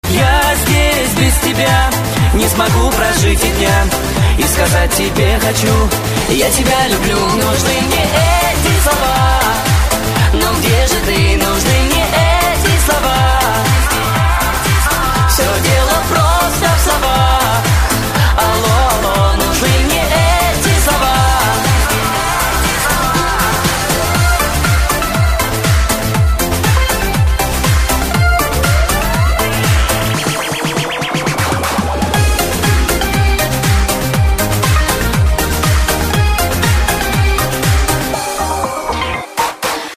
• Качество: 128, Stereo
попса